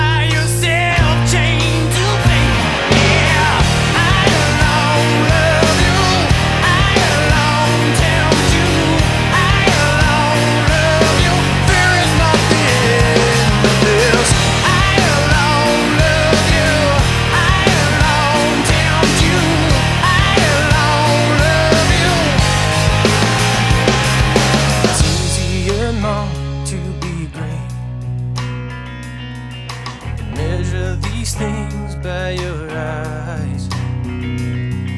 Alternative Rock Adult Alternative
Жанр: Рок / Альтернатива